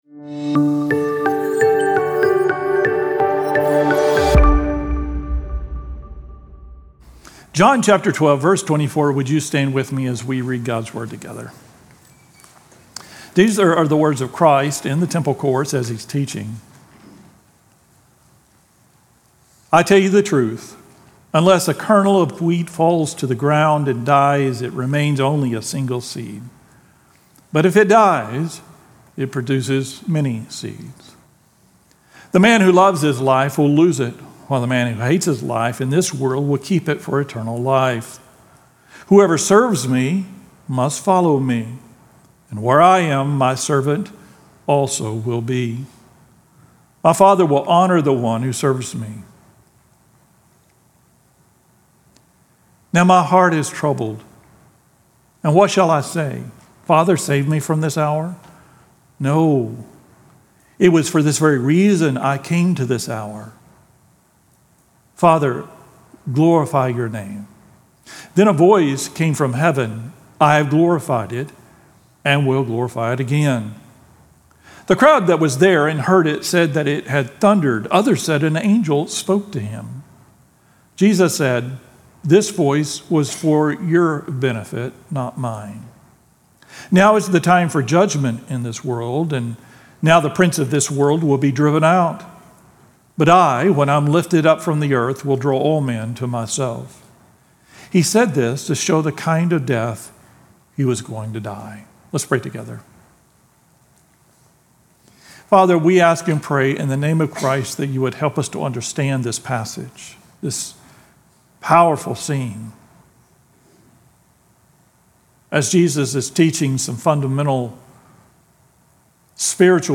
2025-10-05-Sermon-2.mp3